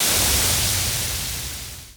Waterspray 2.ogg